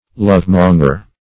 Search Result for " lovemonger" : The Collaborative International Dictionary of English v.0.48: Lovemonger \Love"mon`ger\, n. One who deals in affairs of love.